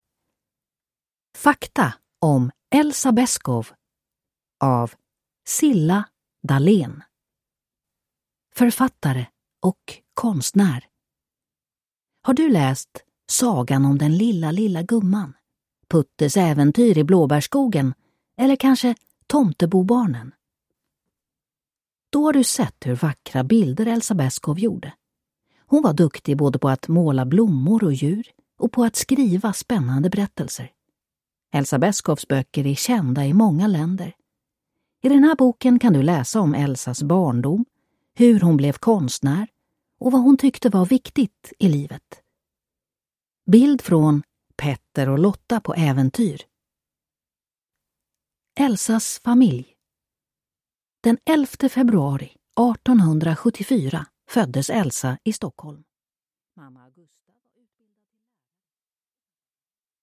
Fakta om Elsa Beskow – Ljudbok